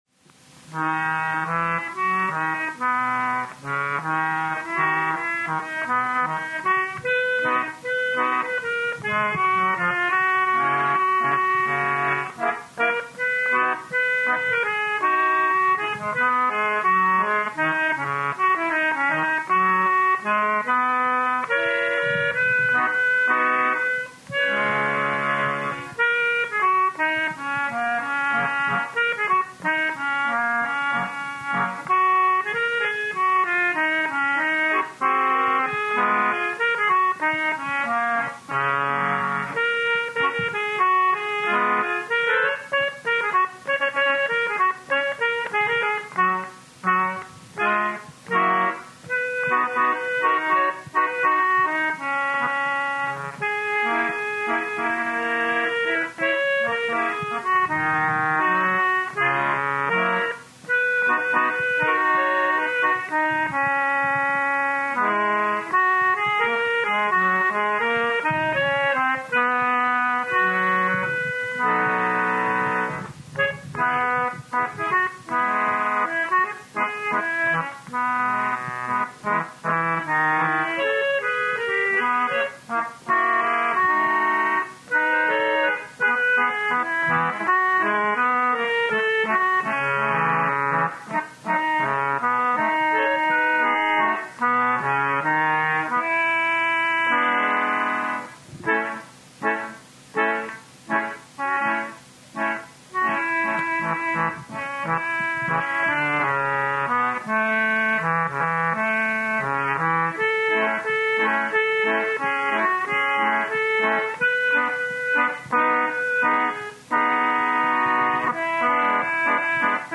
Nevertheless, he gladly shared some other fragments from his once extensive repertory: